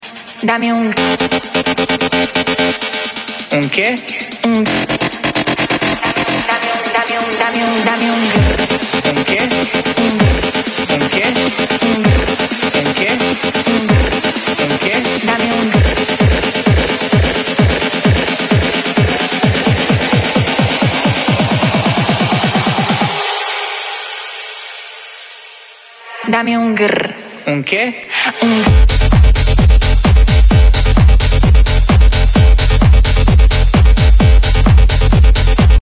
Honda pcx150 x Tridente Cerberus